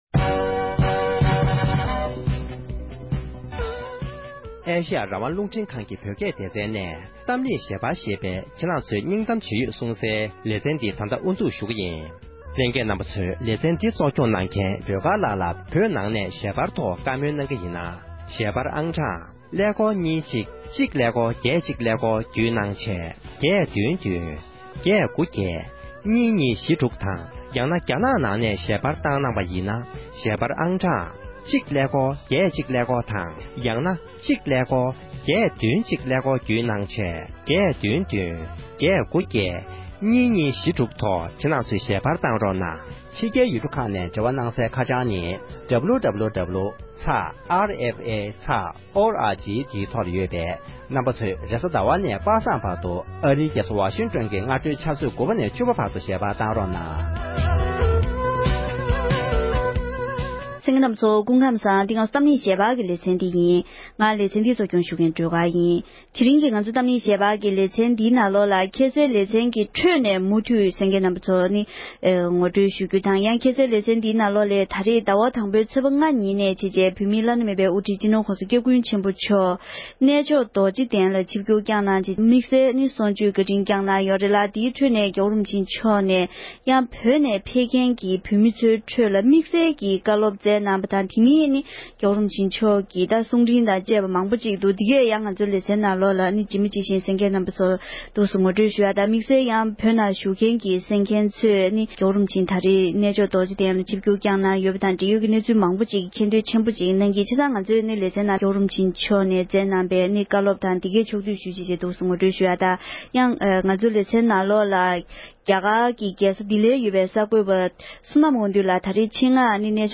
༄༅༎དེ་རིང་གི་གཏམ་གླེང་ཞལ་པར་གྱི་ལེ་ཚན་ནང་འདི་ཟླའི་ཚེས་ལྔ་ཉིན་ནས་ཉིན་གྲངས་བཞིའི་རིང་རྒྱ་གར་གནས་མཆོག་རྡོ་རྗེ་གདན་དུ་བོད་མིའི་བླ་ན་མེད་པའི་དབུ་ཁྲིད་སྤྱི་ནོར་༸གོང་ས་༸སྐྱབས་མགོན་ཆེན་པོ་མཆོག་ནས་དད་ལྡན་ཆོས་ཞུ་བ་ལྔ་ཁྲི་ལྷ་ལ་གསུང་ཆོས་བཀའ་དྲིན་སྐྱངས་གནང་མཛད་ཡོད་པའི་ཁྲོད་ནས་དམིགས་བསལ་བཀའ་སློབ་ཁག་ཕྱོགས་བསྡུས་ཞུས་པའི་དུམ་མཚམས་བཞི་པ་དེར་གསན་རོགས༎